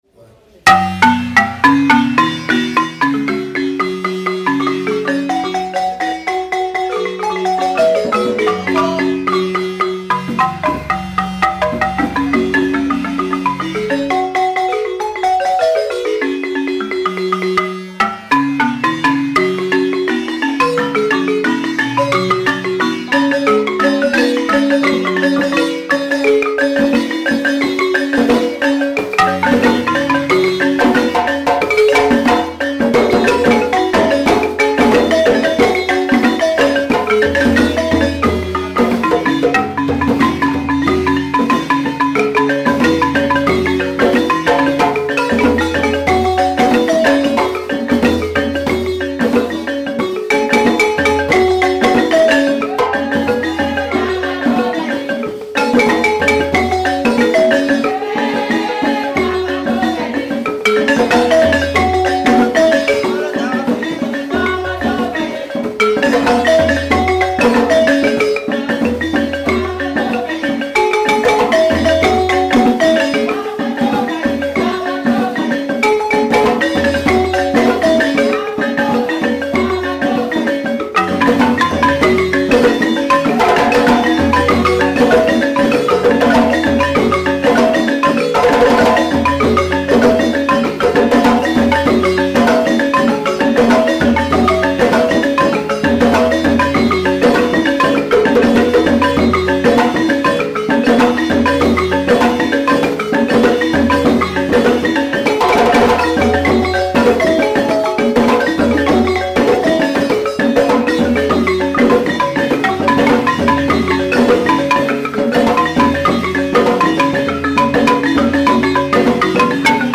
Solo_Balafon.mp3